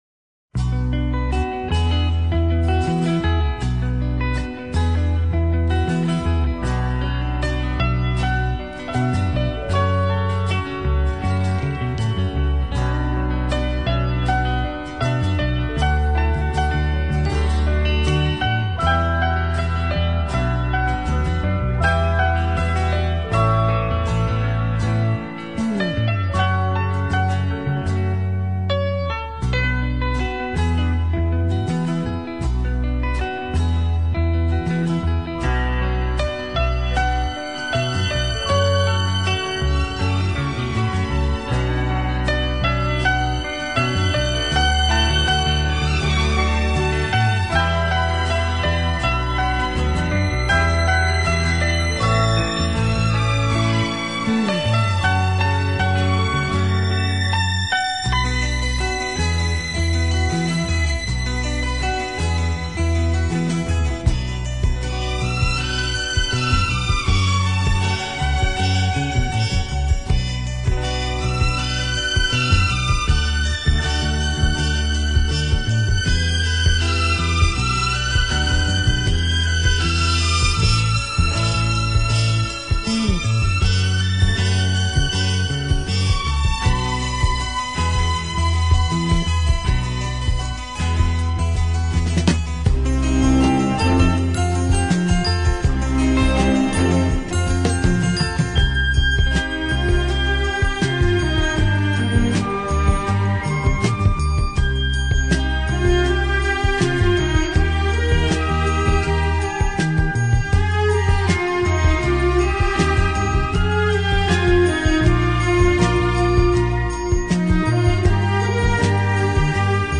音樂類型: 演奏音樂